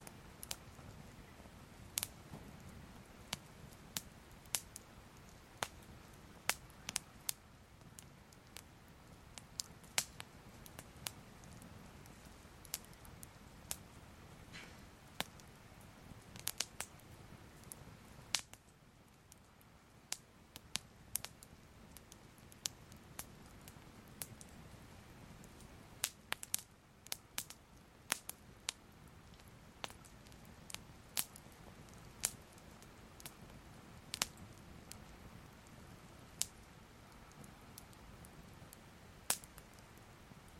На этой странице вы найдете коллекцию звуков горящей свечи: мягкое потрескивание воска, тихое шуршание пламени.
Звук горящей свечи с потрескиванием